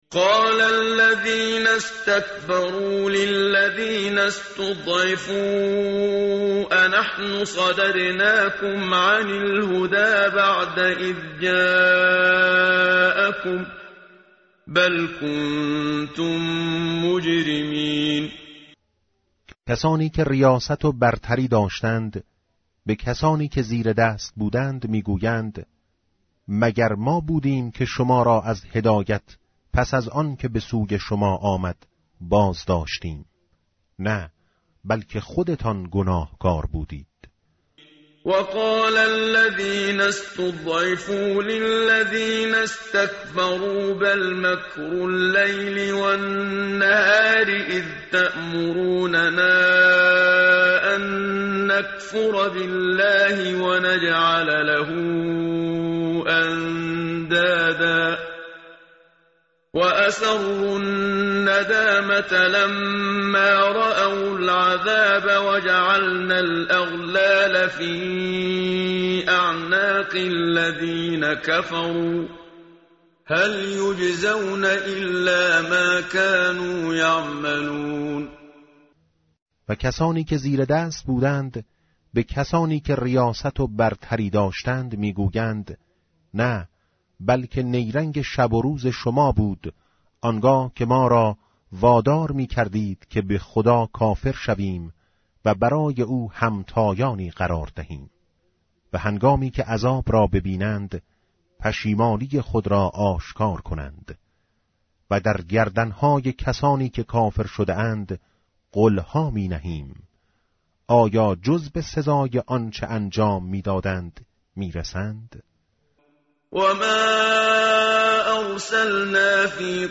متن قرآن همراه باتلاوت قرآن و ترجمه
tartil_menshavi va tarjome_Page_432.mp3